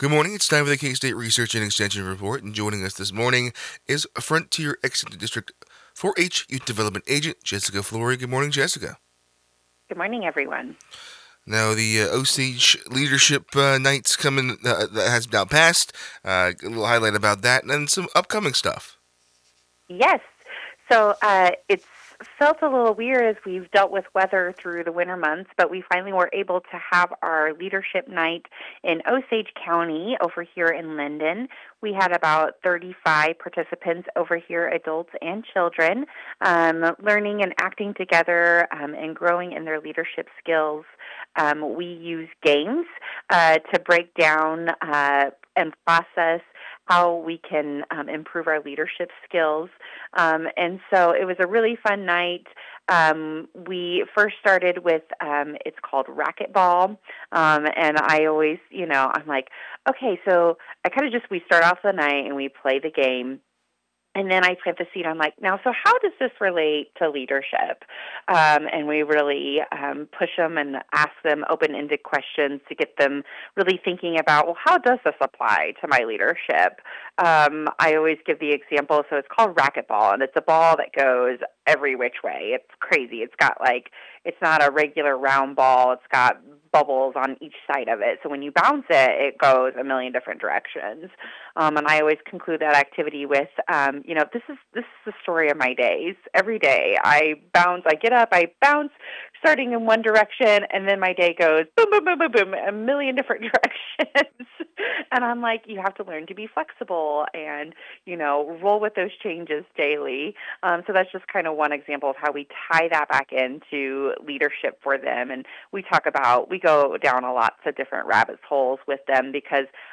KOFO Radio 2026 Recordings – Local Broadcast Audio Archive